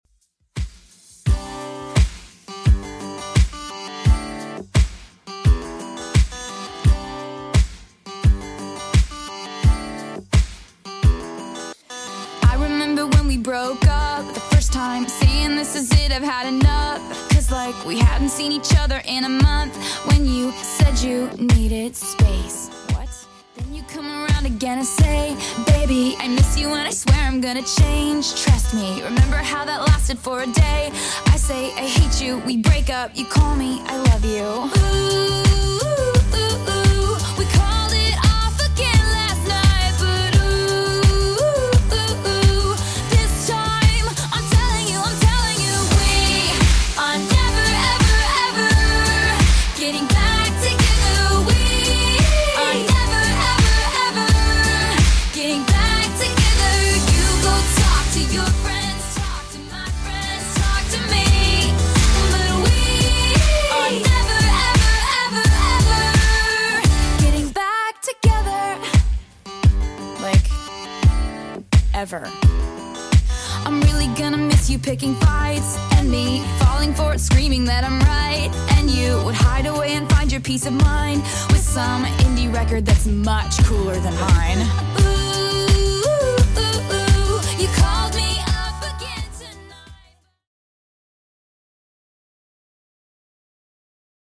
Genre: 80's
BPM: 124